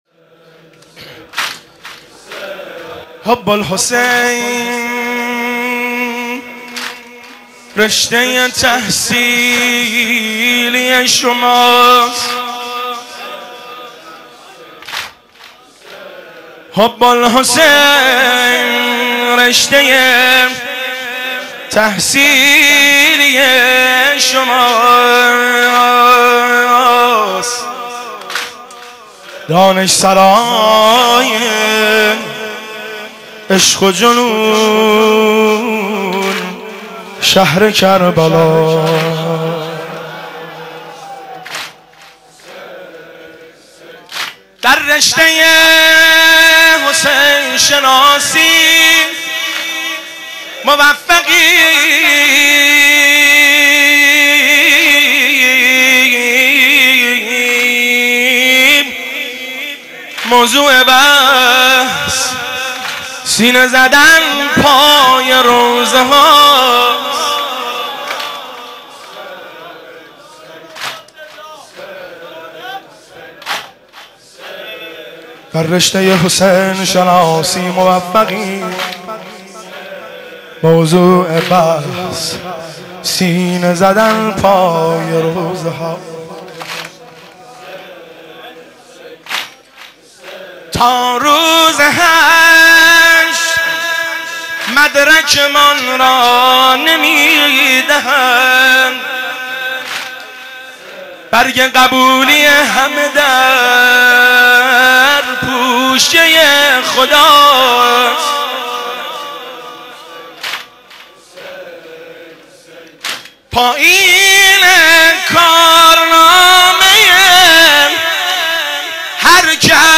مناسبت : شهادت حضرت فاطمه زهرا سلام‌الله‌علیها1
قالب : زمزمه